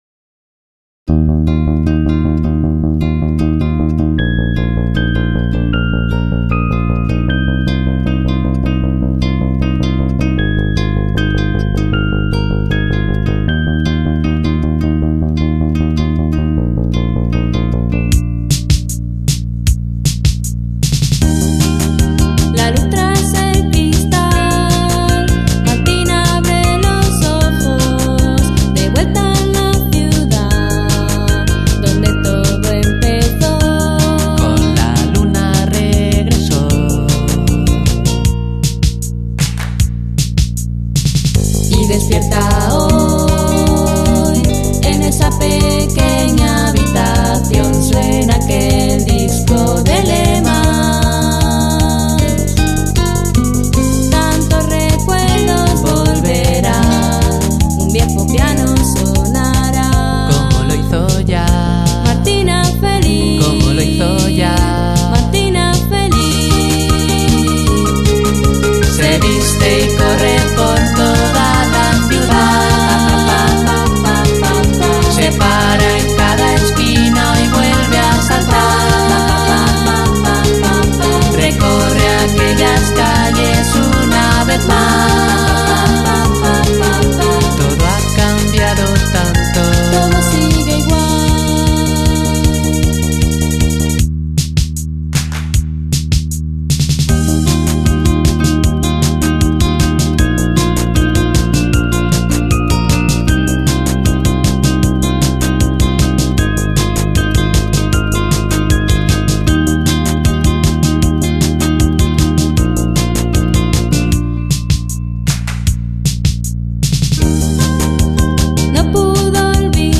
a boy and girl duo